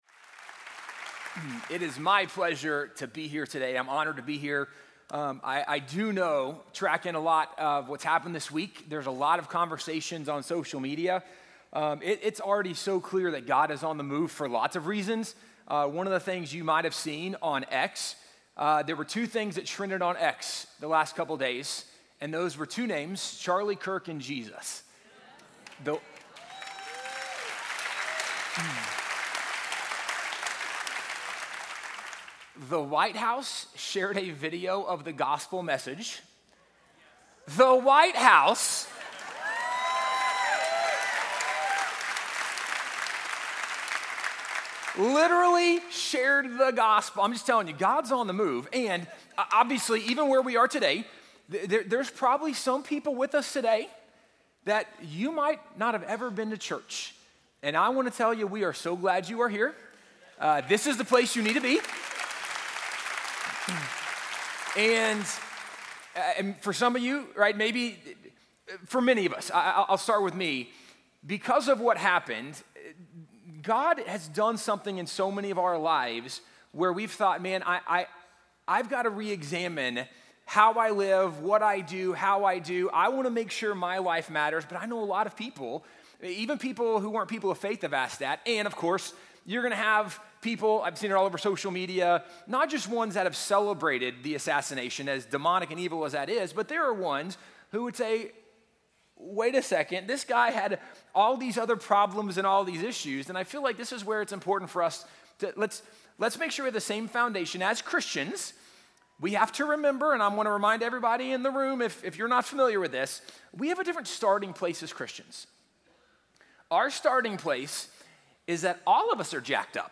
Sermon Overview